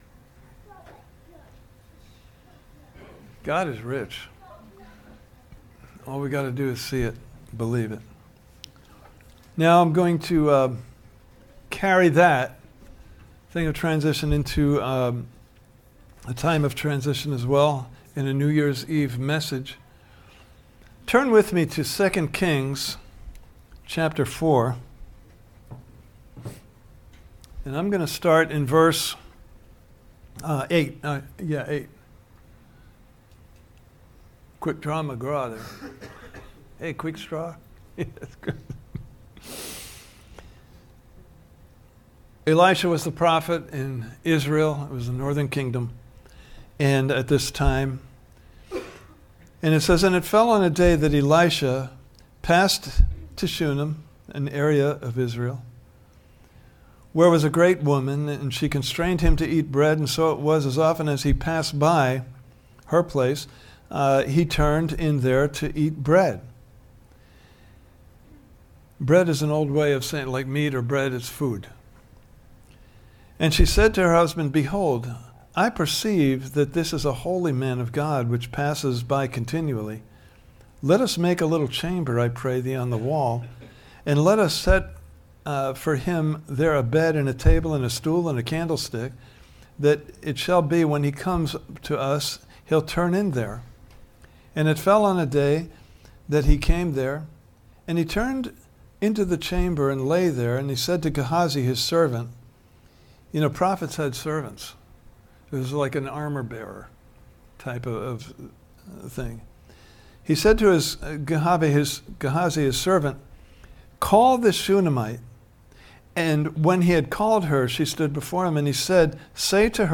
New Year's Eve 2025 Service Type: New Year's Eve « Part 4